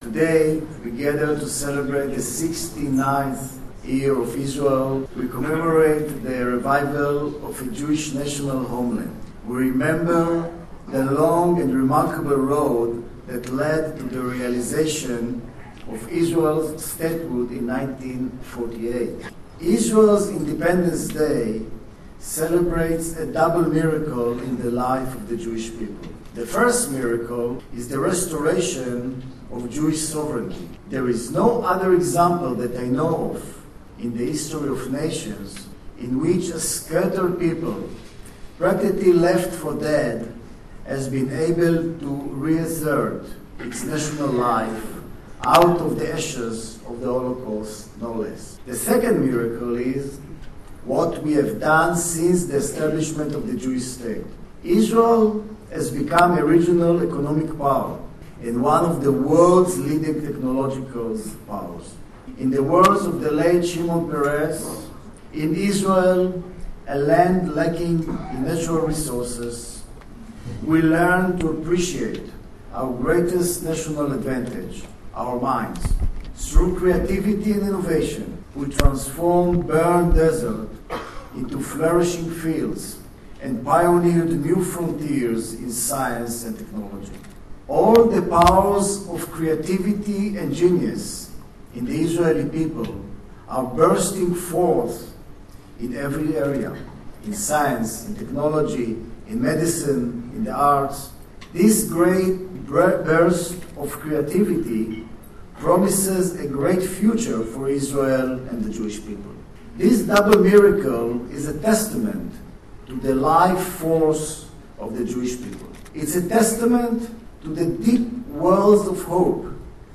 His Excellency Shmuel Ben-Shmuel at a major function for Yom Haatzmaut, Israels 69th Day of Independence.with community leaders and politicians.